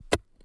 suppression de la reverb sur les sfx de pioche
pickaxe_2.wav